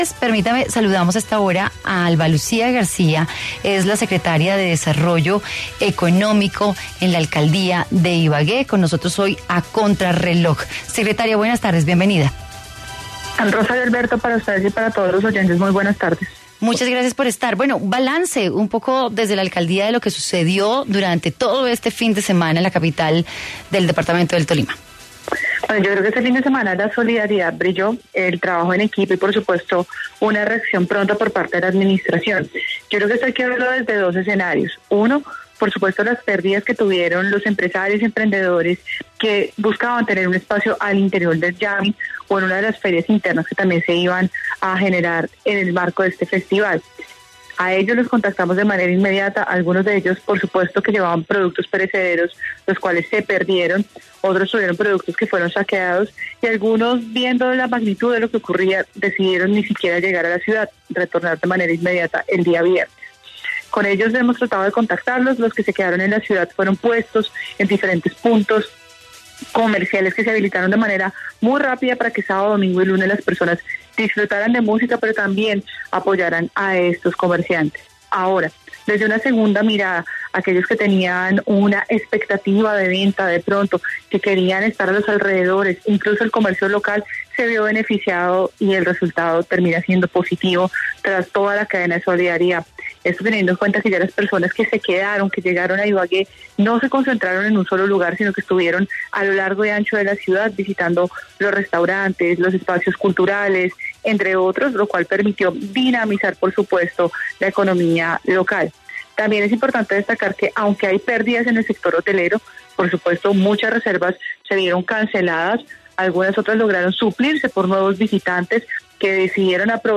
Alba Lucía García, secretaria de Desarrollo Económico de Ibagué habló en Contrarreloj sobre las acciones que implementó la administración municipal para ayudar a los comerciantes afectados por el aplazamiento del Jamming Festival.